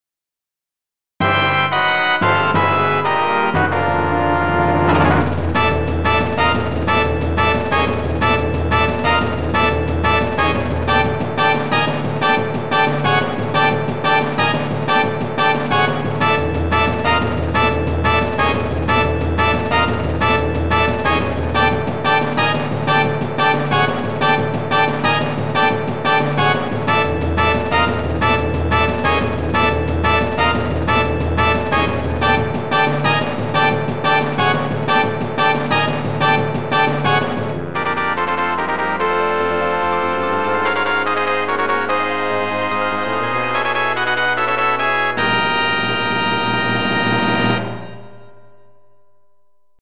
最後にクリアファンファーレのオマケつき。GS音源。